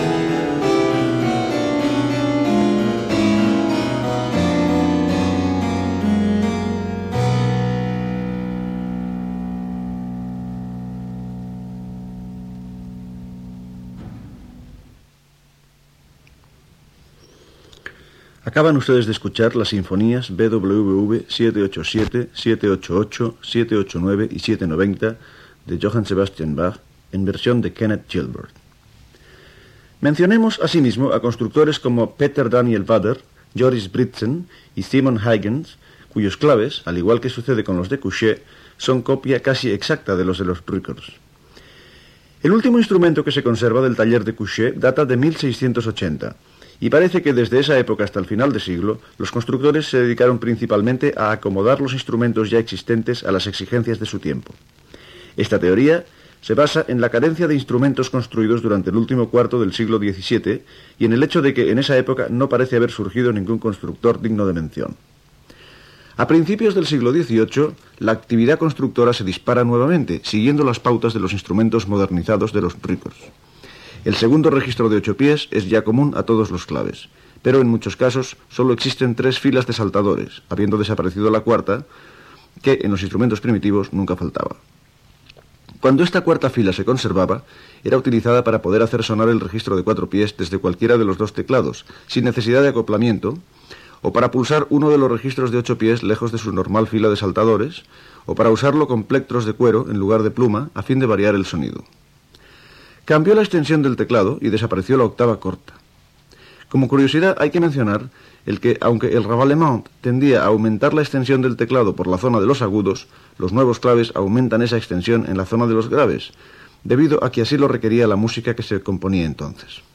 Presentació d'un tema musical Gènere radiofònic Musical